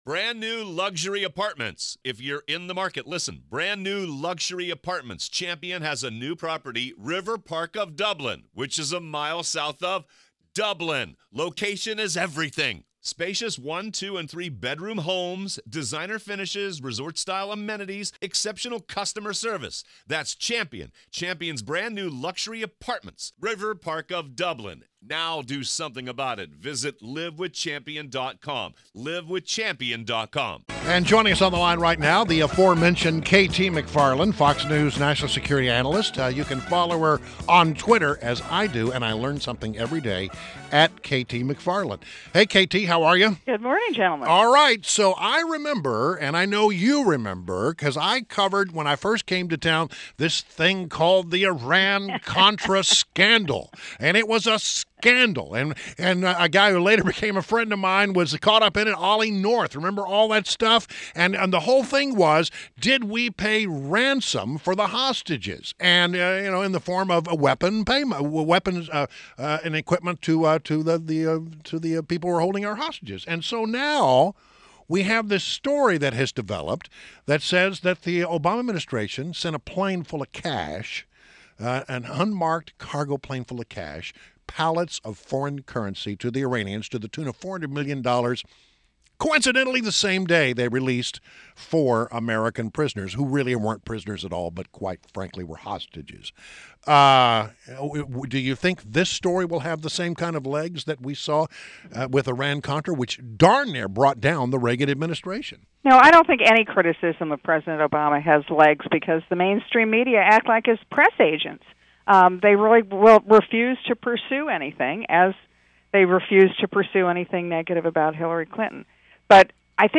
WMAL Interview - KT McFarland - 08.03.16